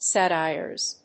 /ˈsæˌtaɪɝz(米国英語), ˈsæˌtaɪɜ:z(英国英語)/